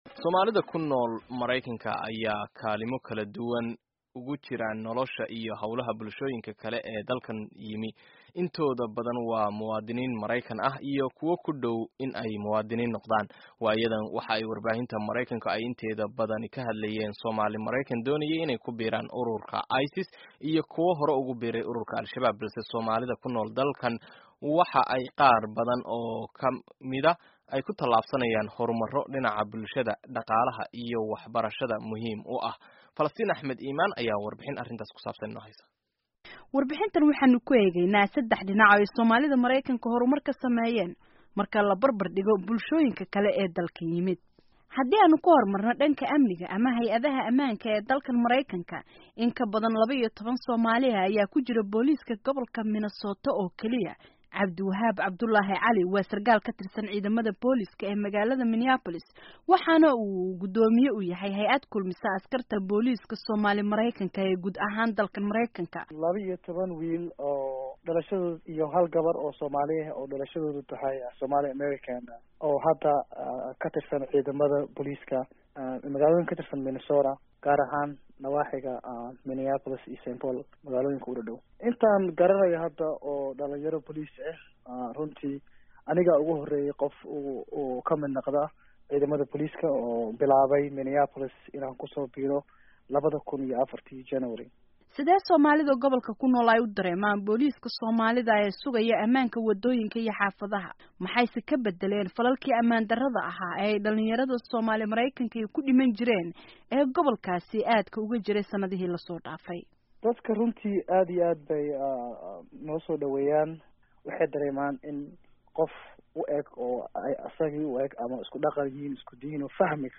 Warbixinta Somali Maraykanta